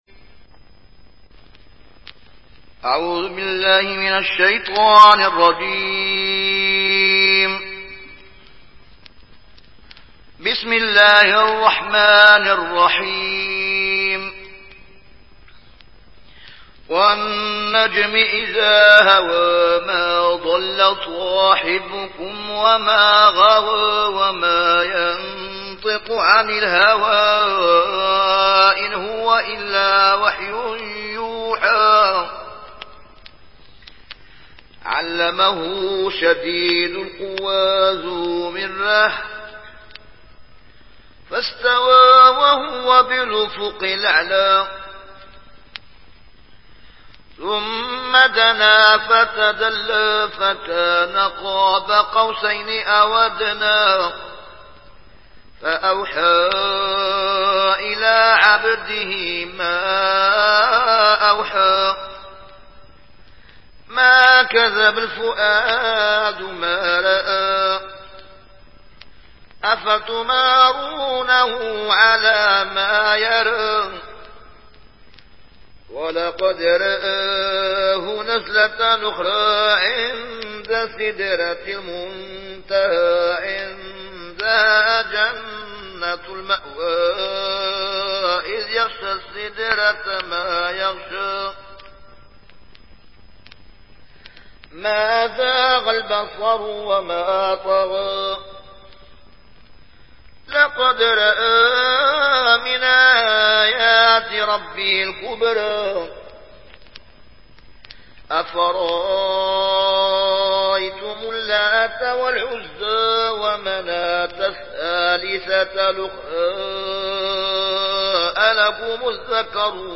رواية ورش